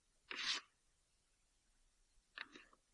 道具 " 拿起放下04
描述：集装箱被捡起并放下。